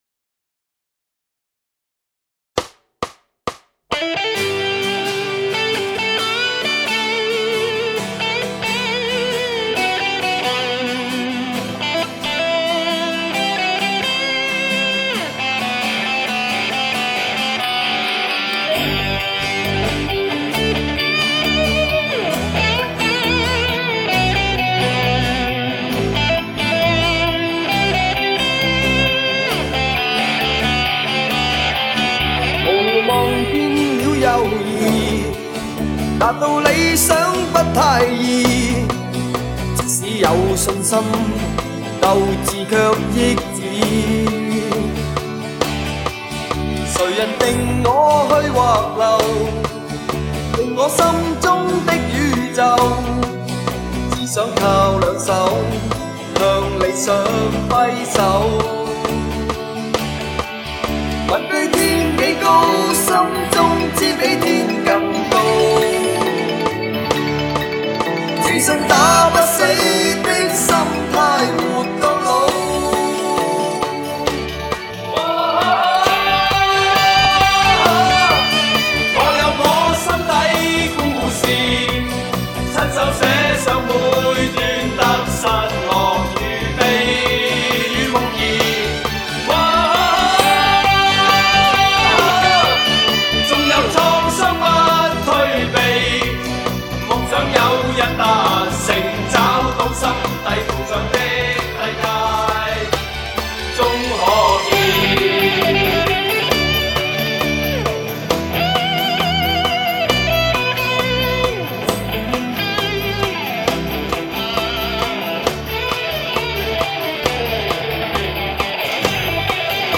纯鼓声